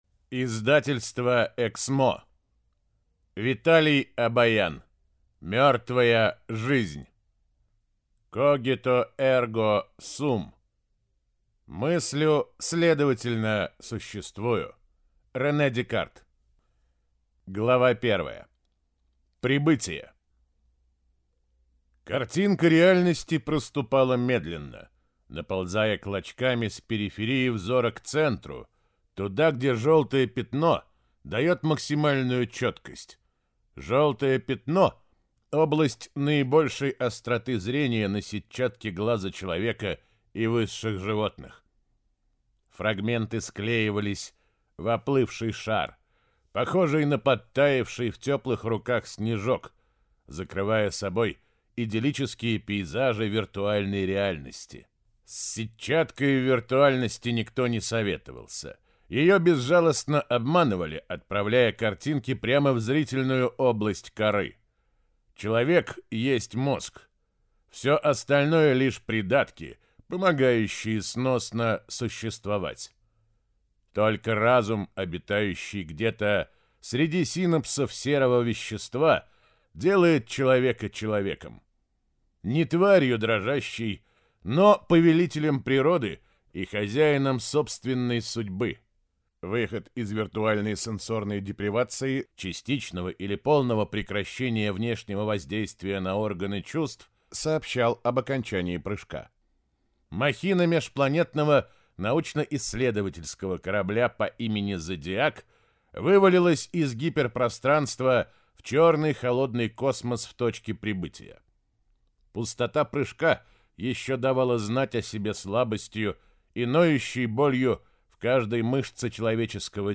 Аудиокнига Мёртвая жизнь | Библиотека аудиокниг